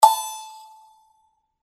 Aufnahme der Lukeme im Abstand von 30 cm